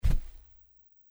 在积雪里行走的脚步声右声道2－YS070525.mp3
通用动作/01人物/01移动状态/02雪地/在积雪里行走的脚步声右声道2－YS070525.mp3